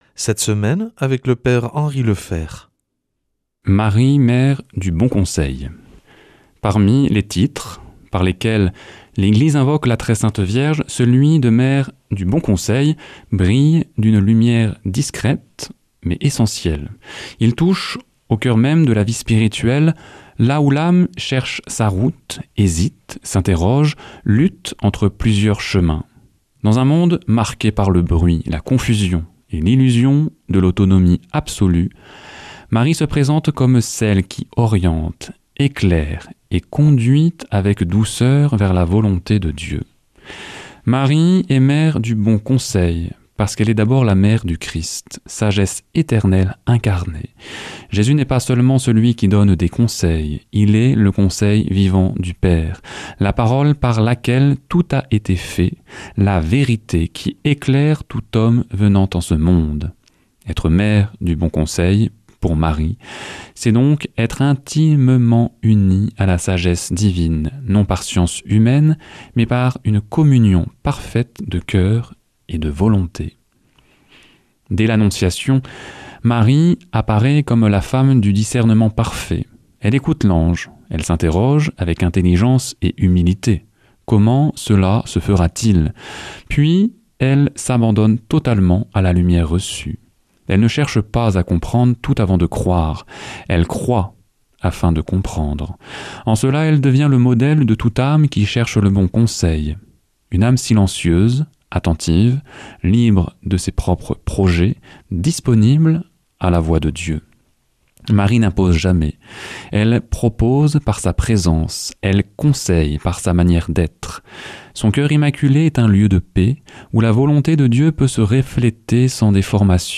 mardi 17 février 2026 Enseignement Marial Durée 10 min